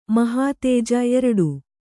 ♪ mahātēja